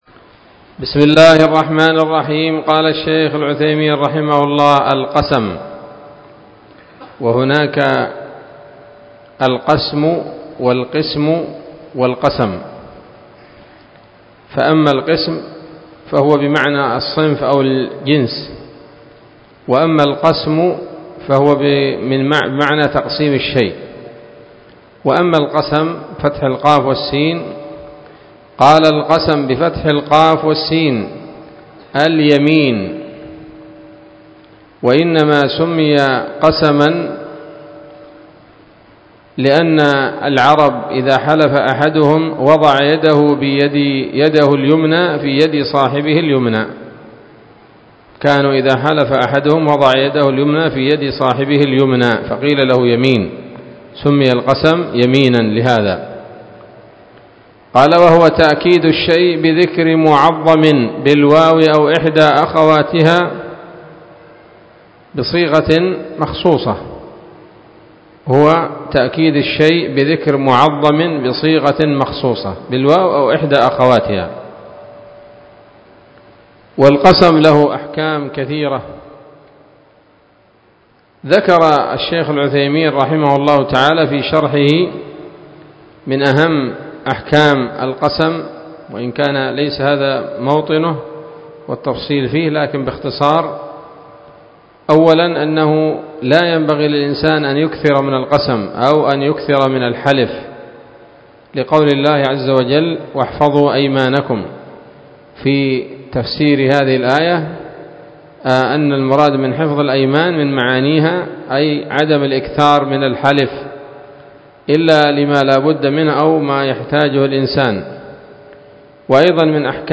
الدرس الحادي والثلاثون من أصول في التفسير للعلامة العثيمين رحمه الله تعالى 1446 هـ